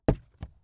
ES_Walk Wood Creaks 15.wav